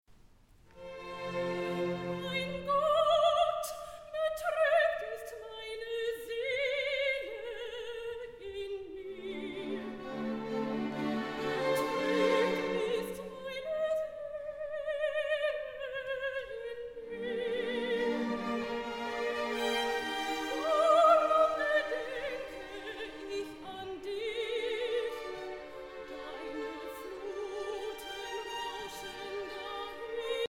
Festliches Konzert zu Ostern